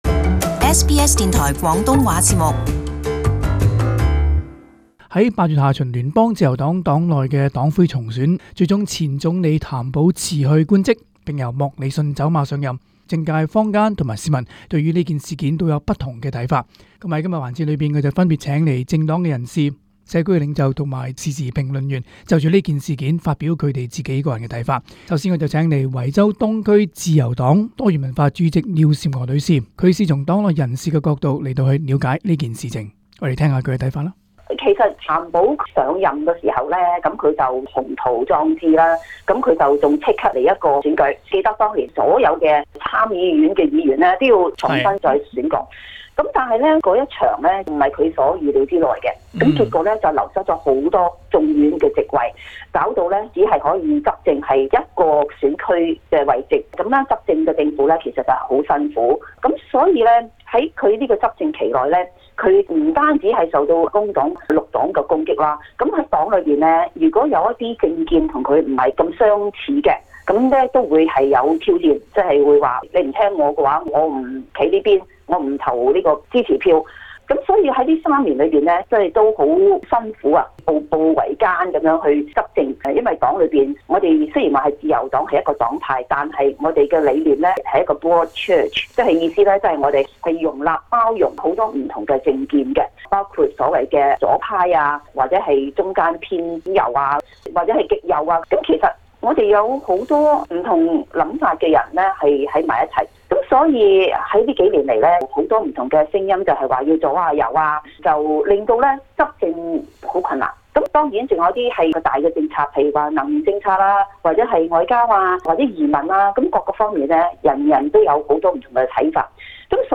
【社区专访】从自由党及社区角度囘看自由党最近的党魁重选事件